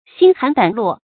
心寒膽落 注音： ㄒㄧㄣ ㄏㄢˊ ㄉㄢˇ ㄌㄨㄛˋ 讀音讀法： 意思解釋： 同「心寒膽戰」。